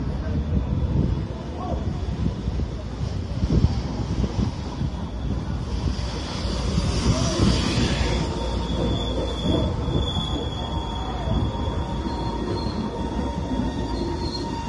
2号列车
描述：当火车经过时，火车运动声音记录在铁路旁边的悬臂式麦克风上
Tag: OWI 火车 声音